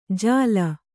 ♪ jāla